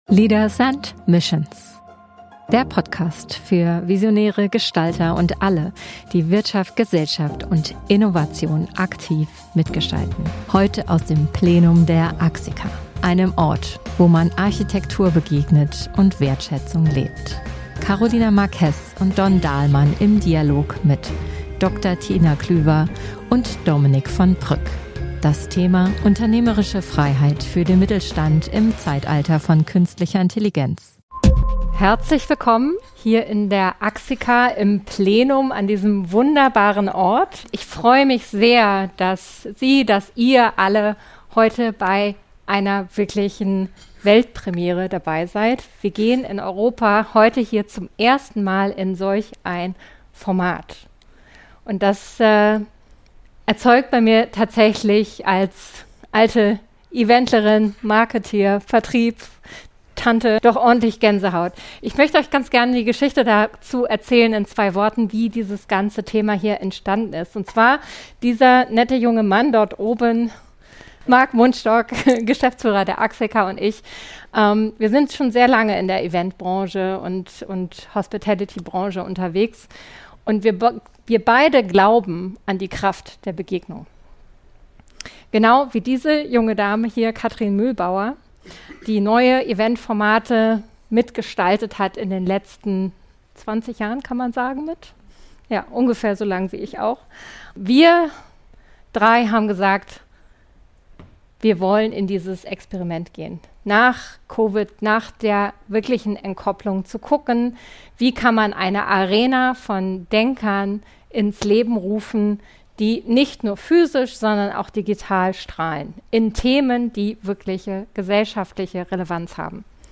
live aus dem AXICA Plenum